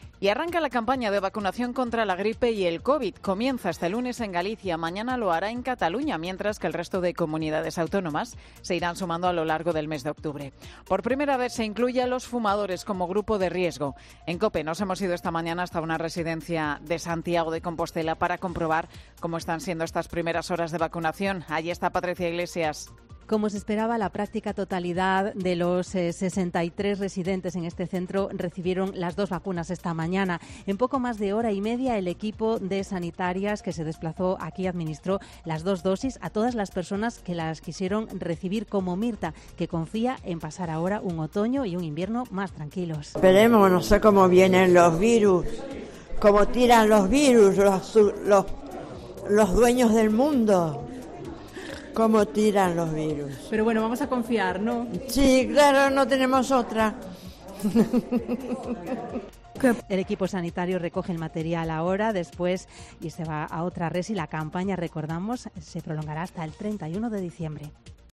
Los compañeros de COPE Santiago se han desplazado hasta una residencia de ancianos de Santiago de Compostela para ver cómo los residentes se vacunaban con total normalidad.